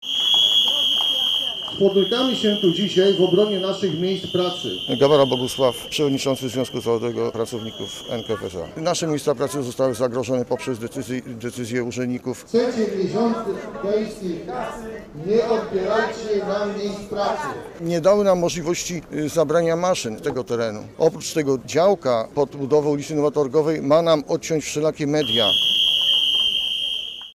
Posłuchaj relacji naszego reportera i dowiedz się więcej: – Władze Łodzi współczują pracownikom firmy Enkev, ich sytuacji, ale odpowiedzialna za nią jest sama spółka – uważa Marek Cieślak, wiceprezydent miasta.